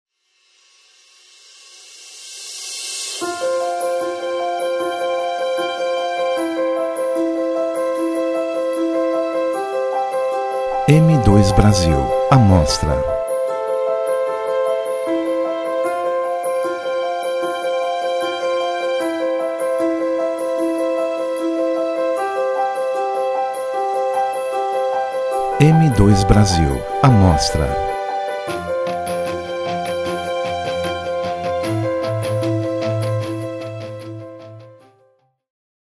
Músicas de Fundo para URA